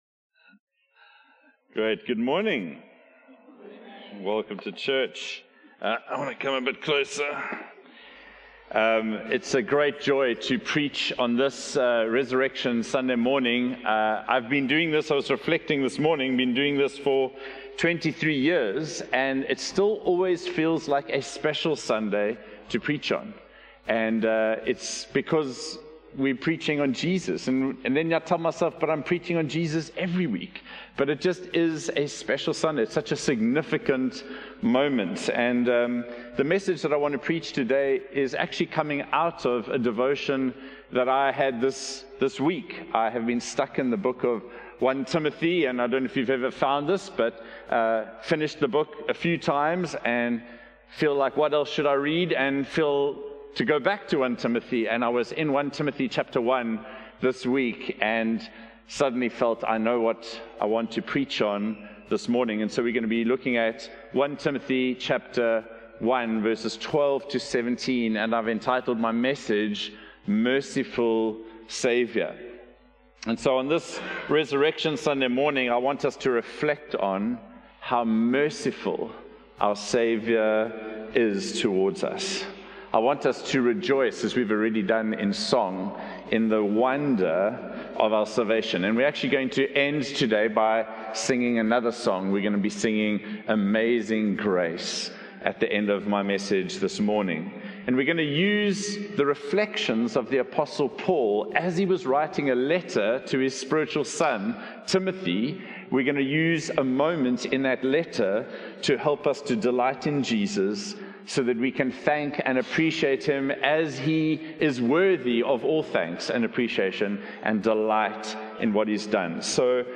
One-Hope-Sermon-Easter-2025.mp3